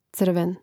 cr̀ven crven